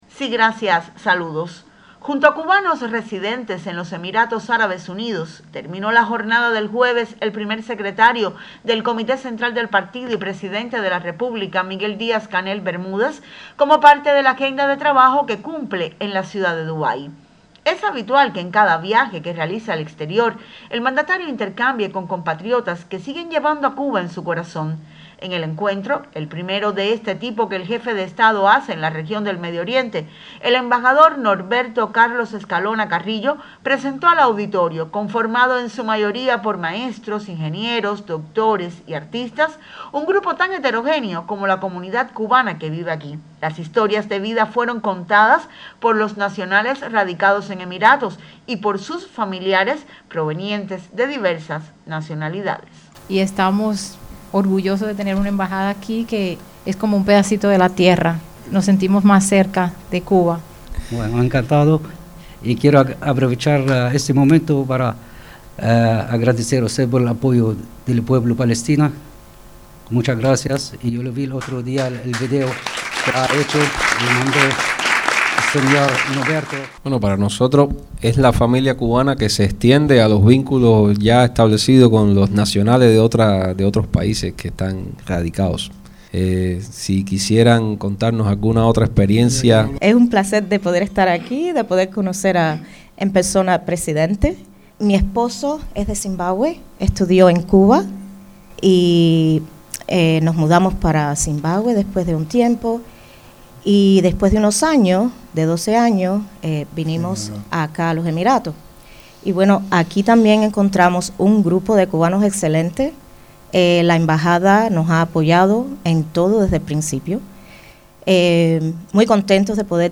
El Primer Secretario del Comité Central del Partido Comunista y Presidente de la República de Cuba, Miguel Díaz-Canel Bermúdez, sostuvo en la ciudad de Dubái un encuentro con representantes de la comunidad de cubanos residentes en Emiratos Árabes Unidos. Sientan la seguridad, la voluntad de que todo puede ser discutido sin que medien prejuicios que atenten contra la unidad, dijo el dignatario a sus compatriotas.